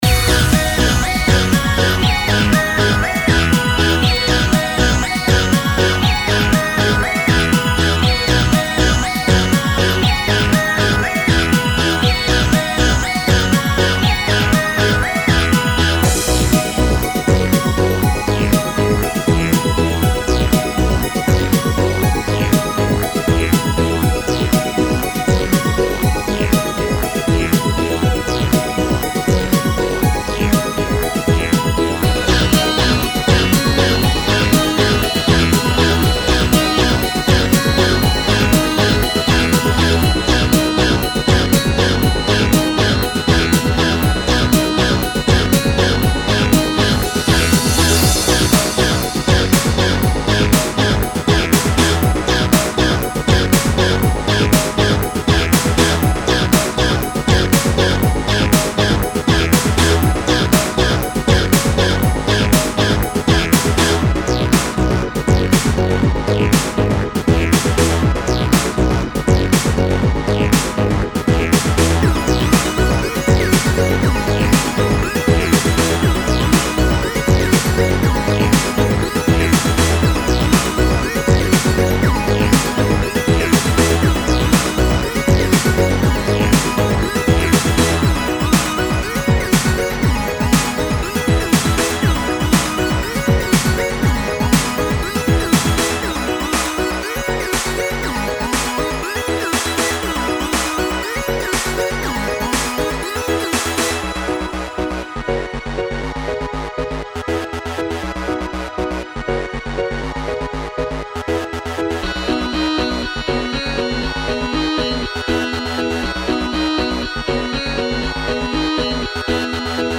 this one is simple, relaxing maybe ?
made with fl studio bpm is 120
this has some nice tonalities in the mix.